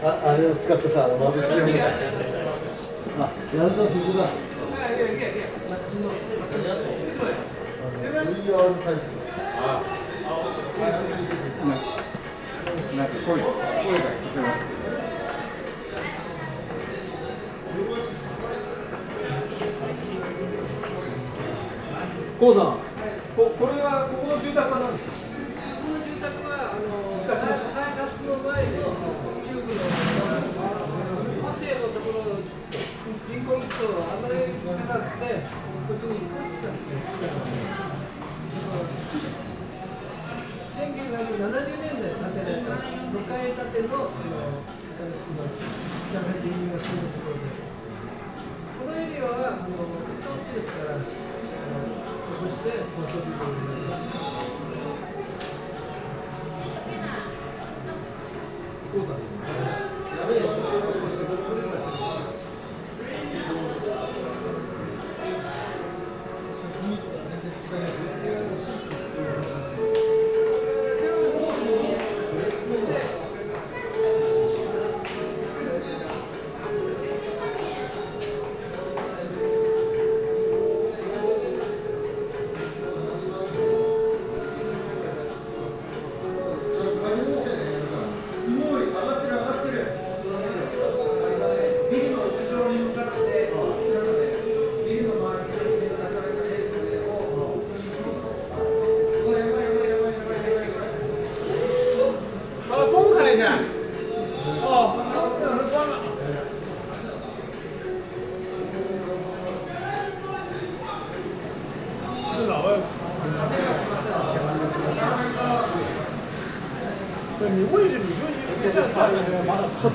oa-china-shanghai-shanghai-tower.mp3